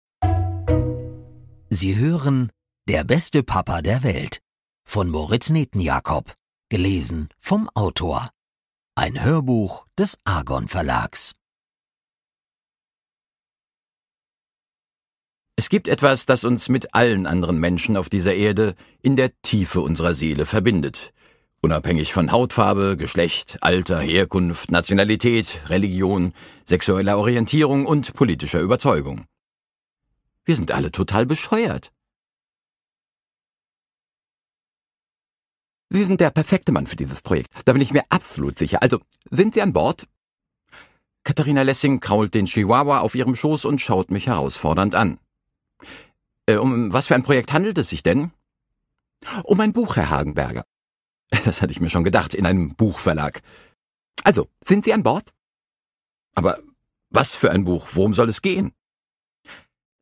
Produkttyp: Hörbuch-Download
Gelesen von: Moritz Netenjakob